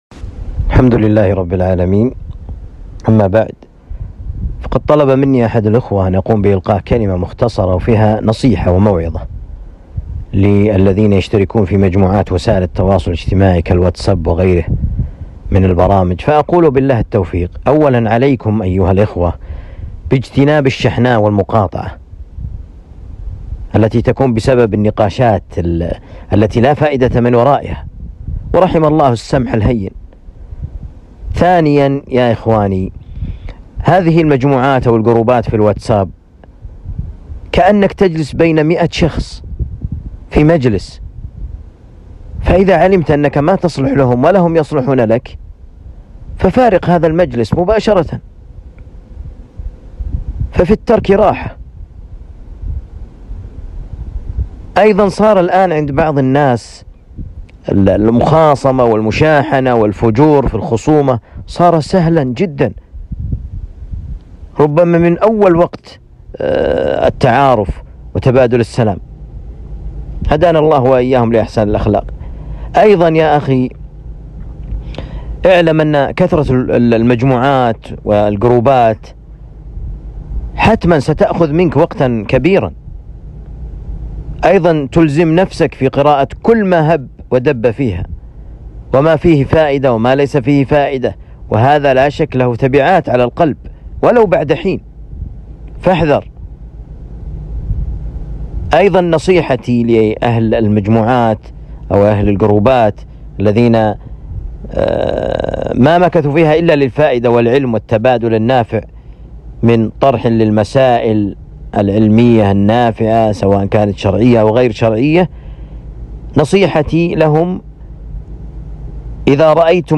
كلمة مختصرة موجهة للإخوة في المجموعات